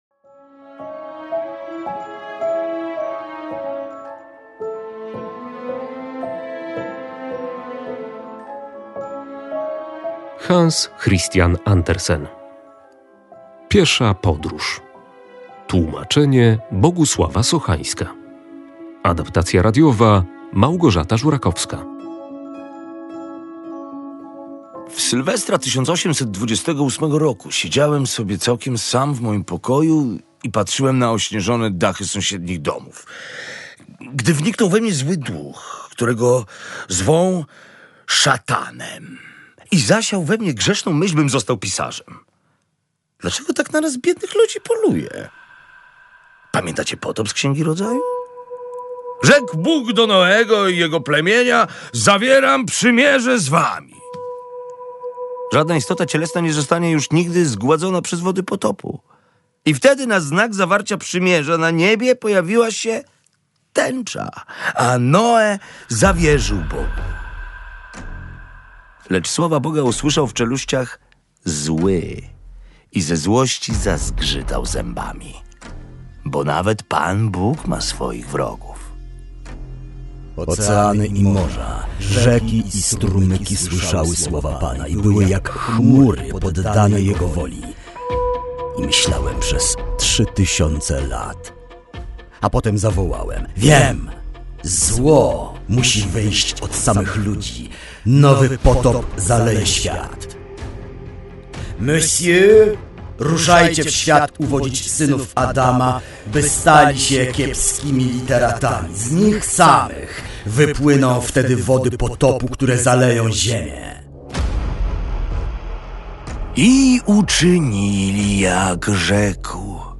Na podstawie książki powstało słuchowisko